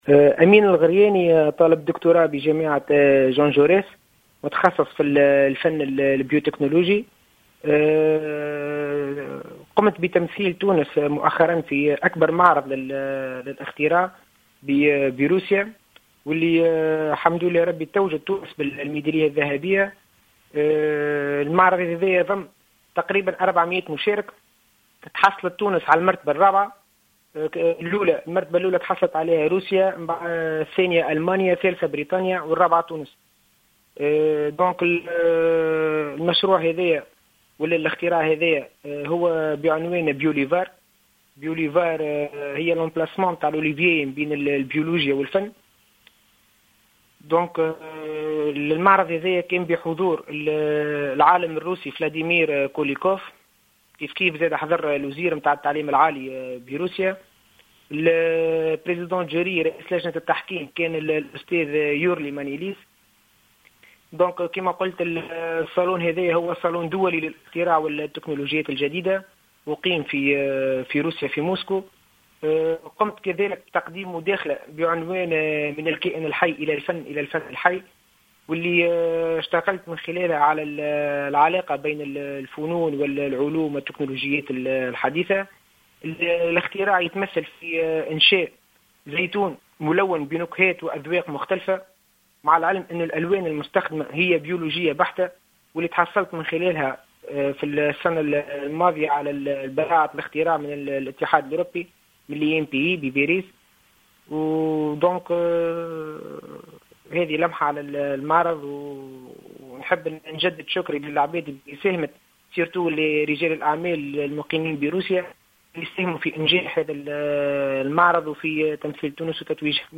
في تصريح هاتفي